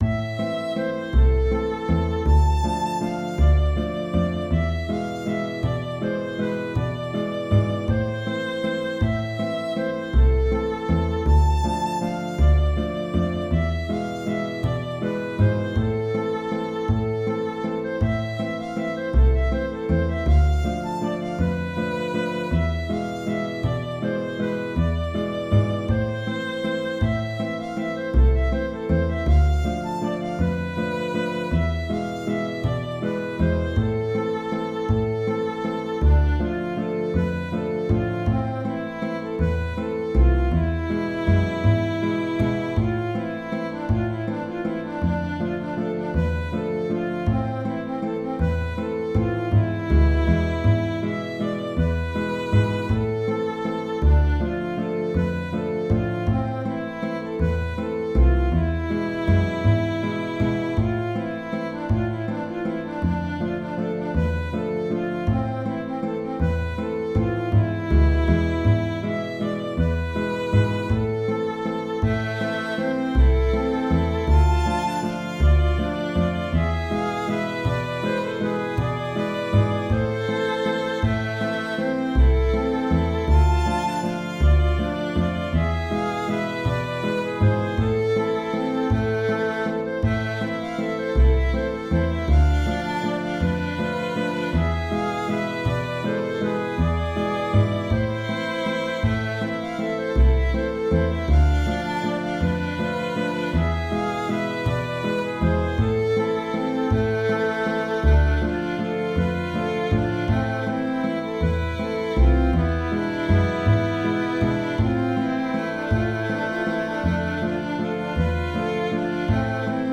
Valse des Chevaux de Bois
Puis avec le contrechant. A la troisième reprise, c’est le même contrechant avec quelques notes de liaison en plus.